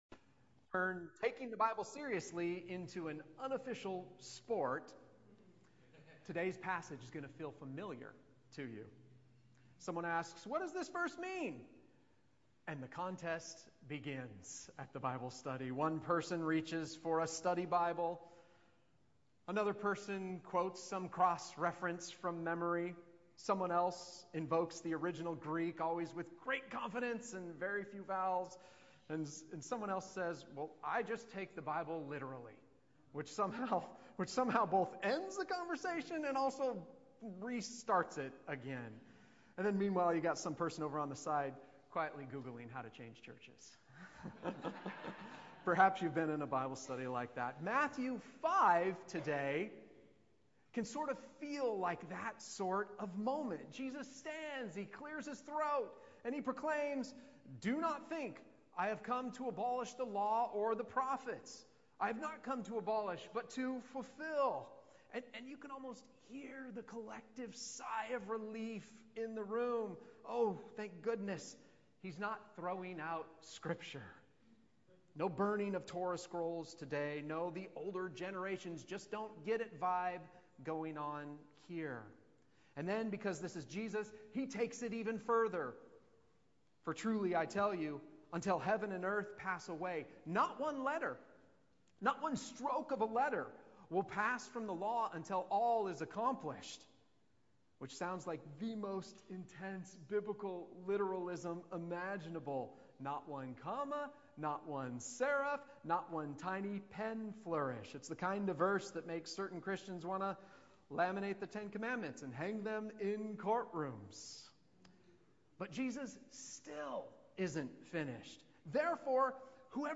Matthew 5:13-20 Service Type: 10:30 Hour - Sermon A reflection from Matthew 5 on Jesus' teachings about a righteousness that exceeds that of the scribes and Pharisees